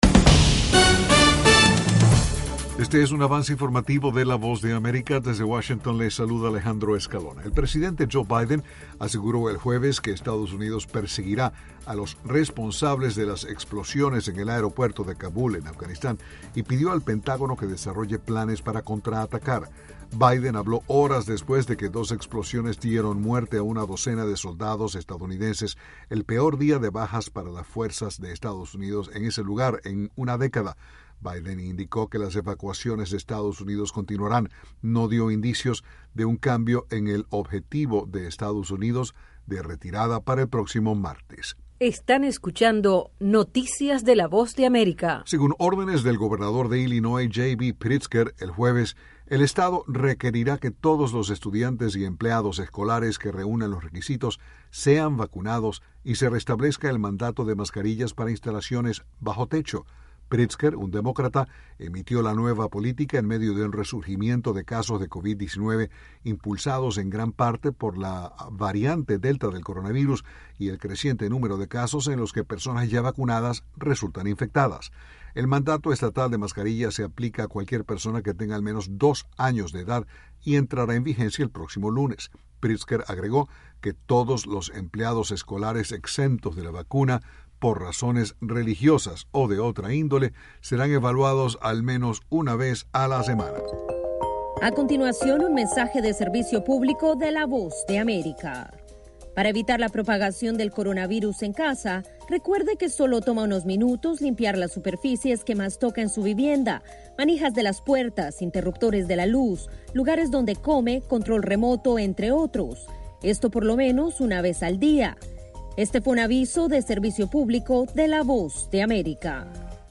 Con la voz entrecortada, el presidente Joe Biden aseguró el jueves que Estados Unidos perseguirá a los responsables de los ataques suicidas en el aeropuerto de Kabúl y pidió al Pentágono que desarrolle planes para contraatacar.